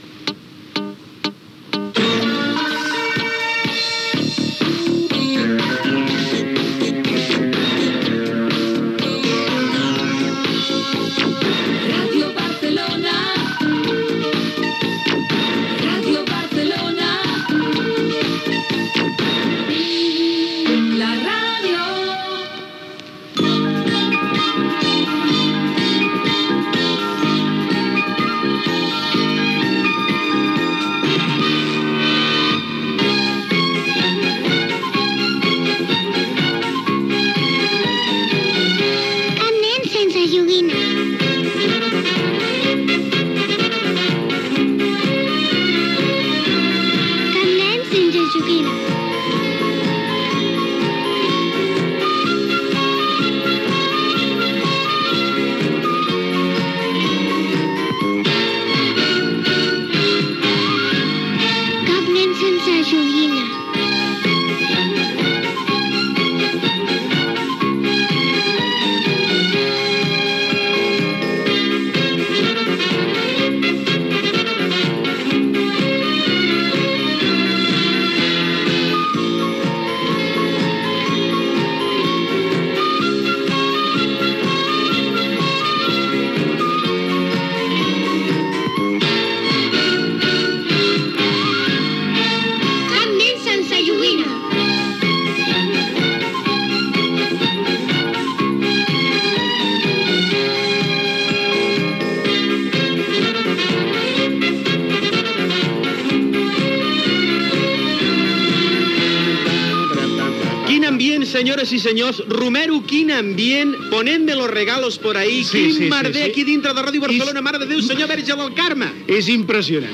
Indicatiu de l'emissora, sintonia del programa amb identificació, presentació inicial sobre els preparatius del programa, la invitació a la participació, etc.